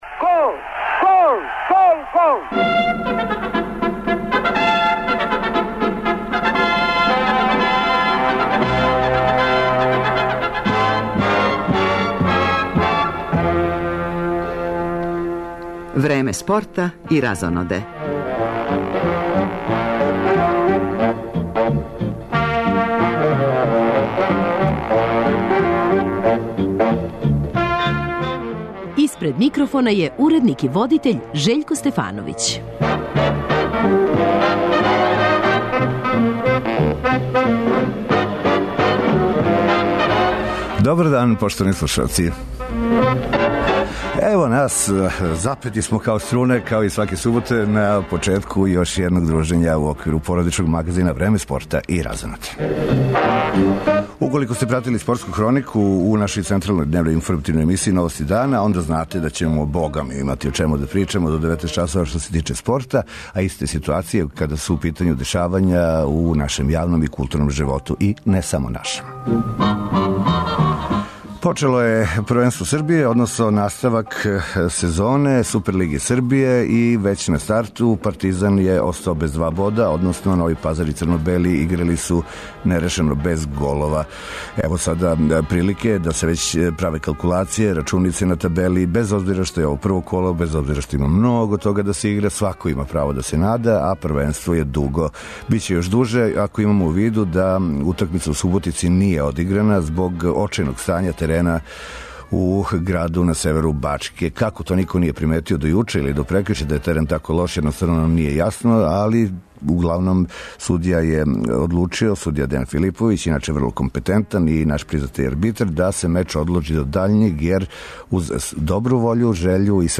Уз остале, гост у студију нам је Дадо Топић, који једноставно не зна за предах када су концерти у питању.